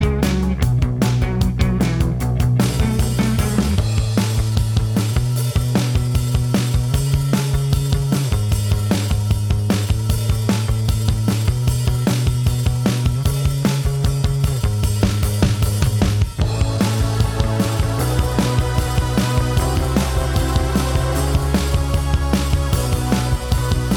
No Guitars Pop (2010s) 4:49 Buy £1.50